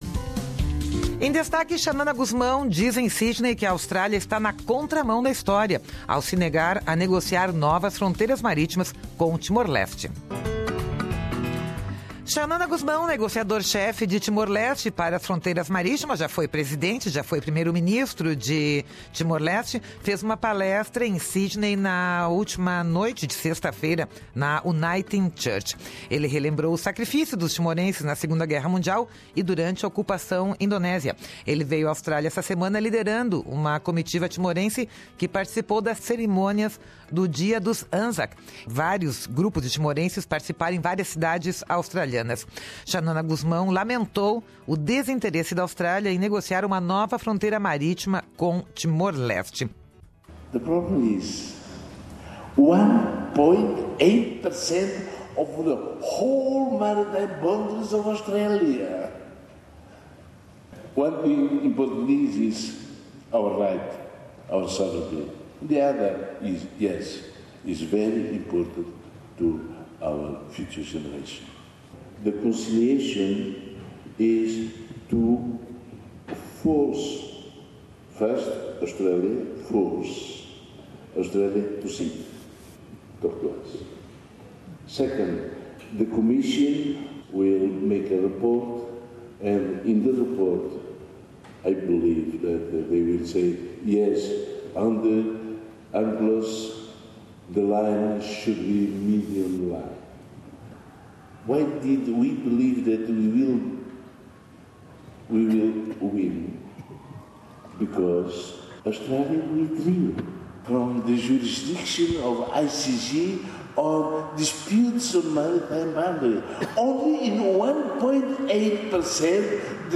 Em Sydney, Xanana Gusmão disse que a Austrália está na contra-mão da história, ao se negar a negociar novas fronteiras marítimas com Timor-Leste. Ouça aqui parte do discurso de Xanana Gusmão